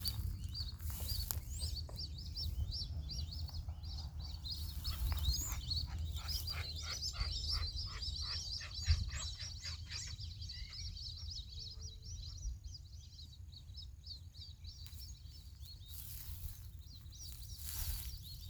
Pato Cutirí (Amazonetta brasiliensis)
Nombre en inglés: Brazilian Teal
Localización detallada: Colonia Ayuí, Paso del Águila
Condición: Silvestre
Certeza: Observada, Vocalización Grabada